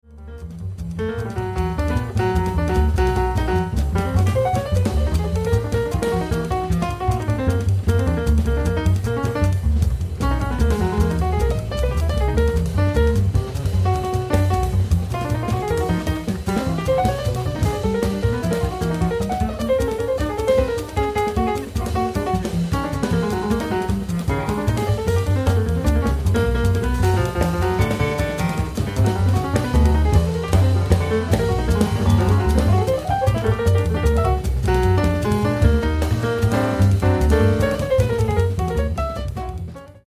Recorded at Red Gables Studio 8th, 9th May 2008